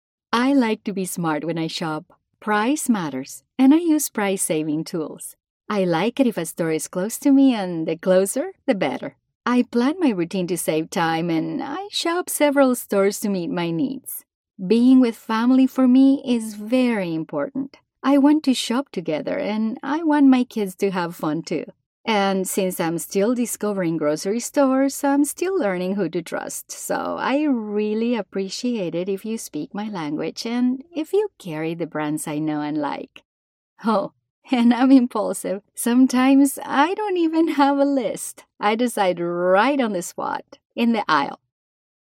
Neutral Latin American Spanish female voice.
Sprechprobe: Sonstiges (Muttersprache):